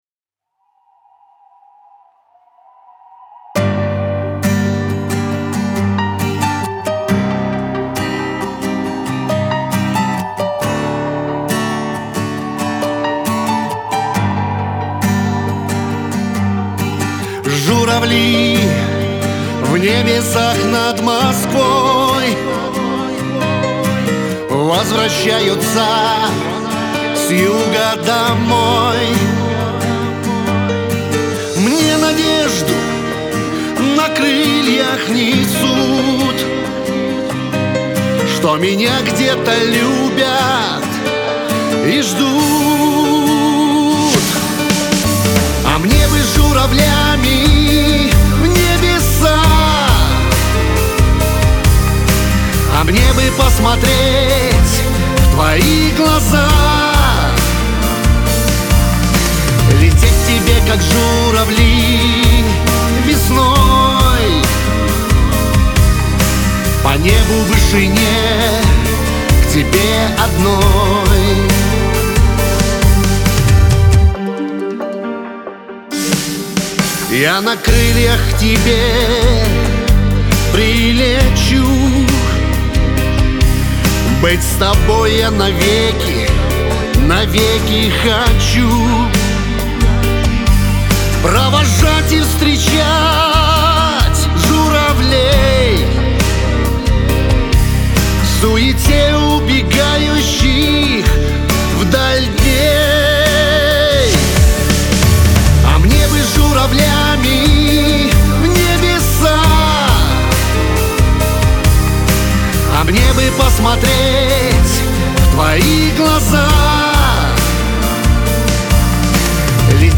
эстрада
диско , Лирика